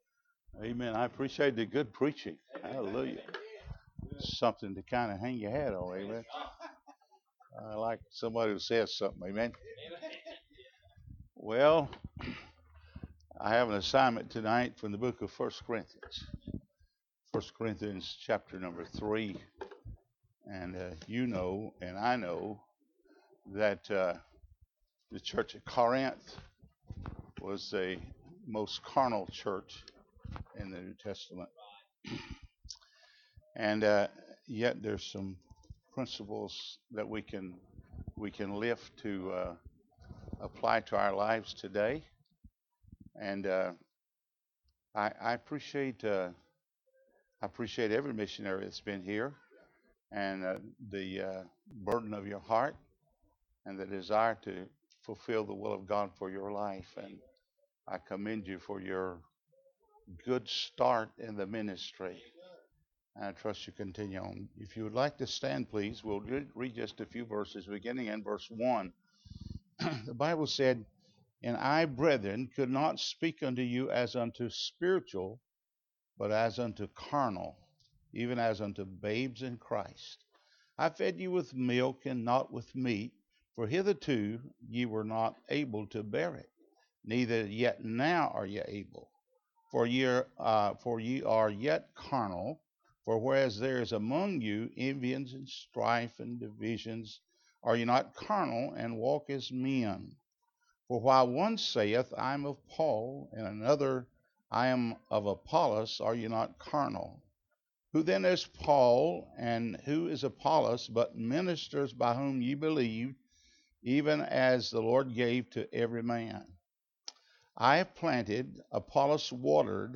1 Corinthians 3:1-9 Service Type: Mission Conference Bible Text